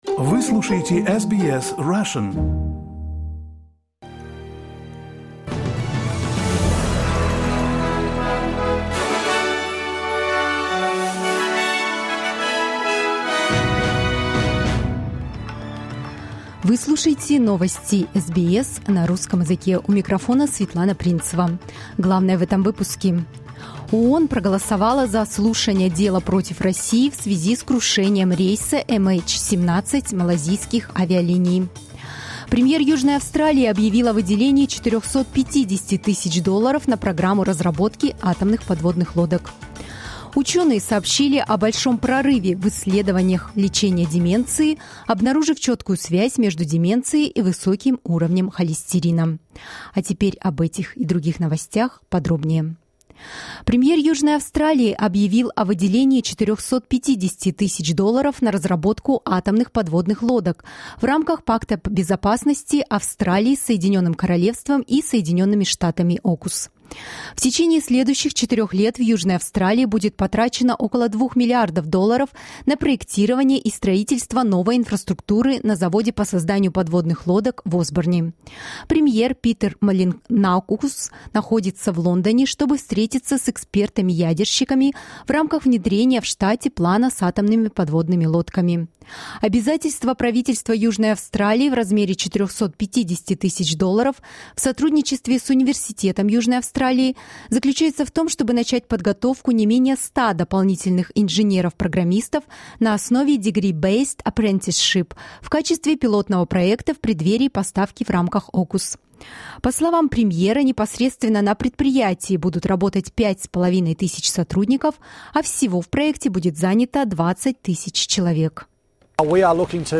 SBS news in Russian — 18.03.2023